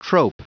Prononciation du mot trope en anglais (fichier audio)
Prononciation du mot : trope